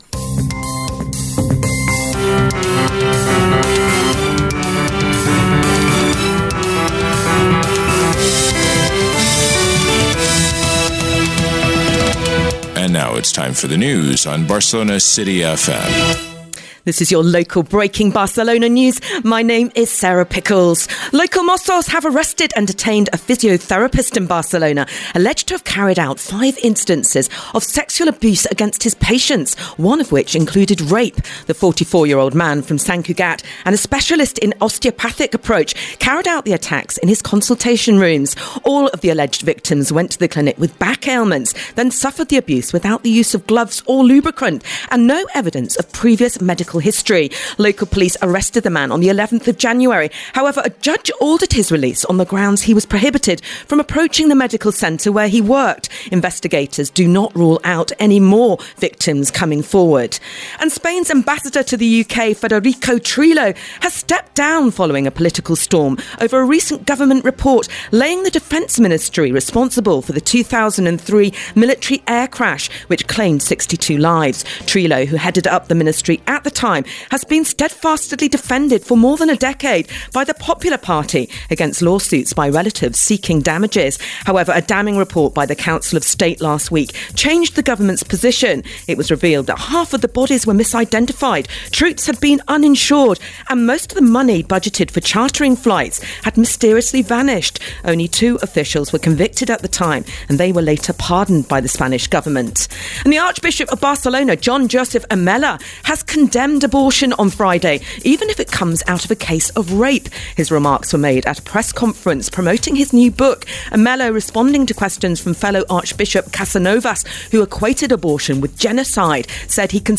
Careta del programa, notícies diverses, careta de sortida, esports (Premiere League)
Informatiu